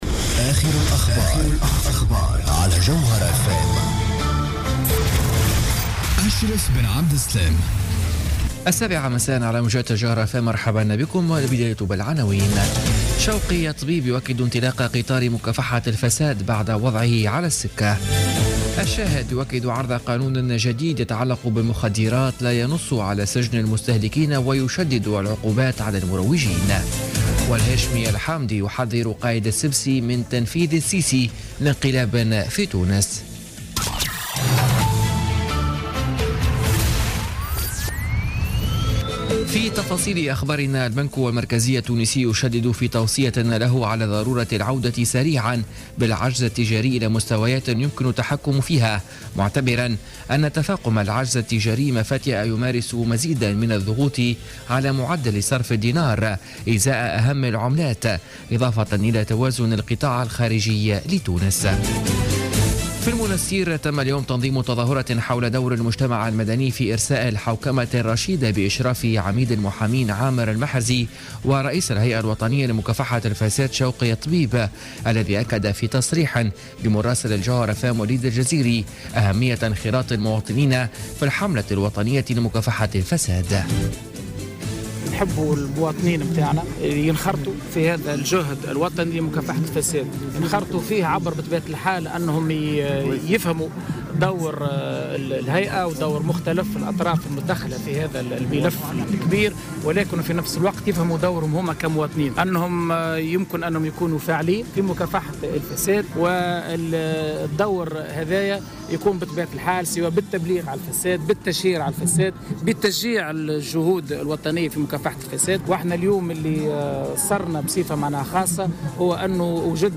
نشرة أخبار السابعة مساء ليوم السبت 18 فيفري 2017